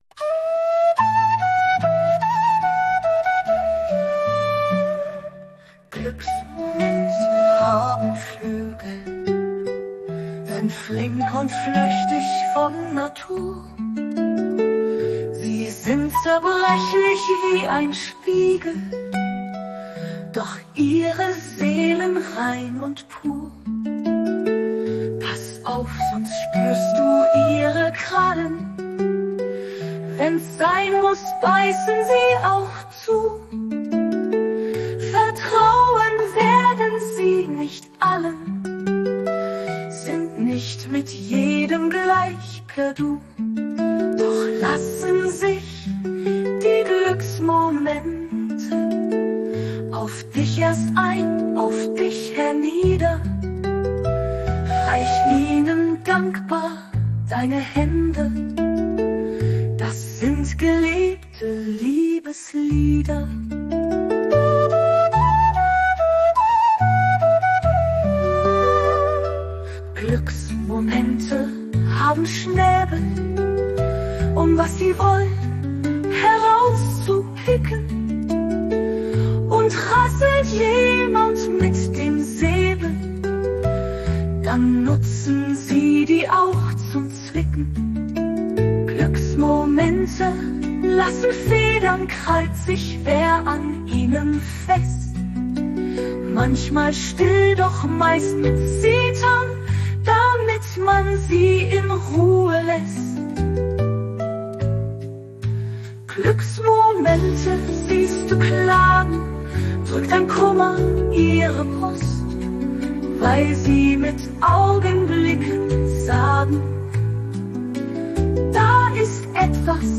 Vertonung mittels KI von suno